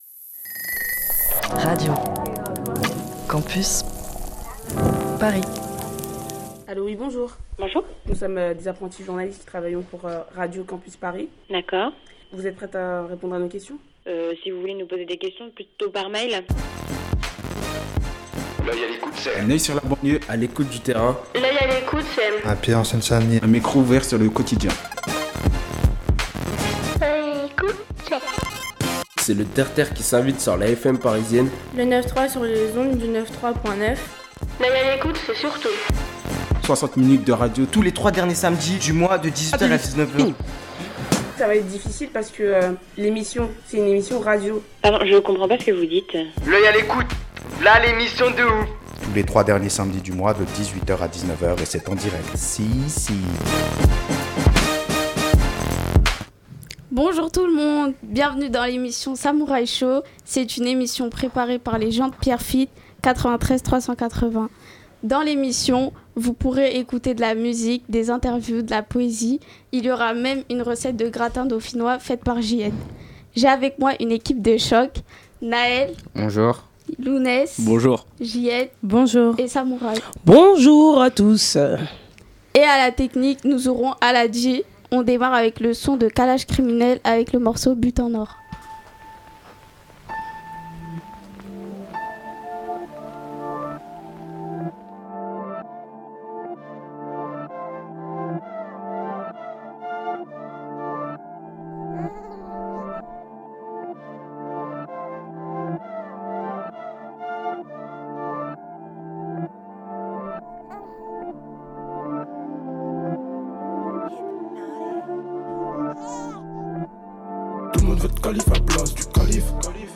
le Samouraï Show est une émission proposée réalisée et animée par des jeunes de la ville de Pierrefitte sur Seine.
ils vous présentent leurs vies , leur ville et la musique qu'ils aiment. Vous trouverez de la poésie , des interviews, des micro-trottoir et de la bonne humeur .